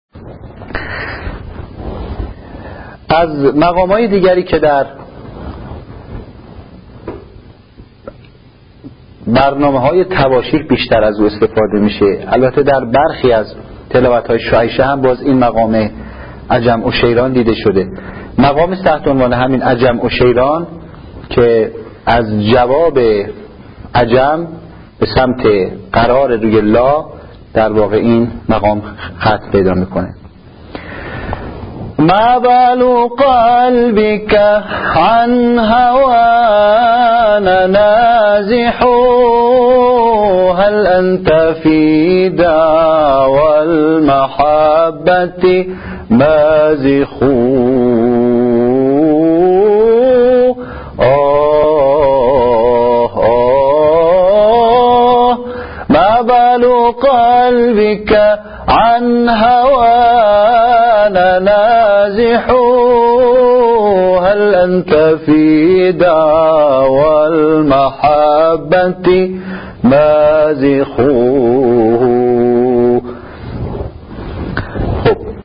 مقام عجم عشیران